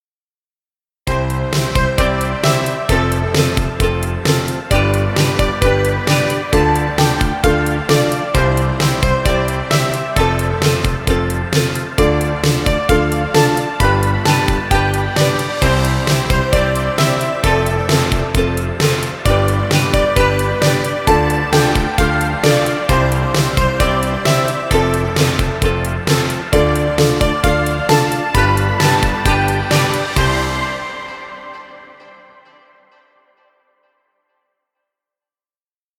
Children music. Background music Royalty Free.
Stock Music.